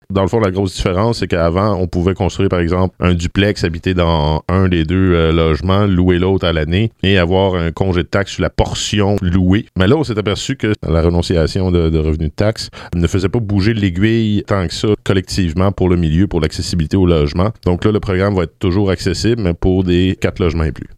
Le maire Antonin Valiquette explique ce qui changera pour les bâtisseurs.